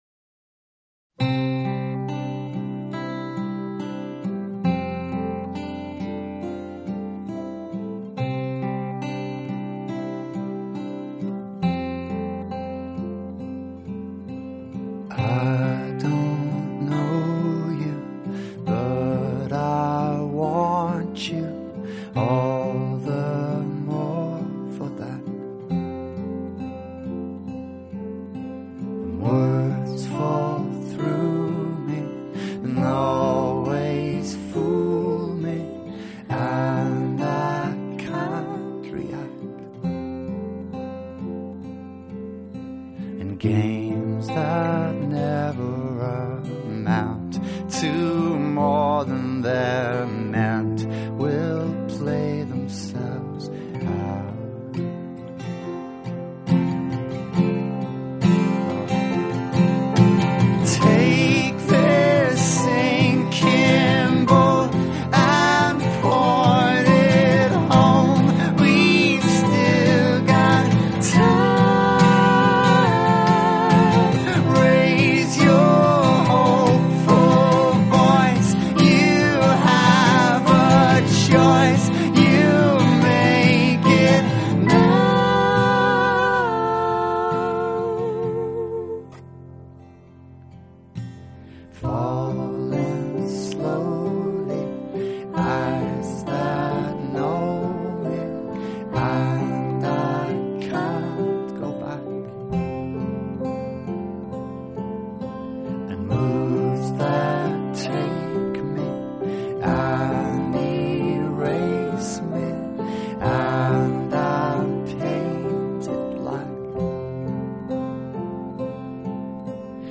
以及钢琴与吉他浑然天成的配和， 使一切显得那么简约自然、清新浪漫而极富美感。
安静的让人寂寞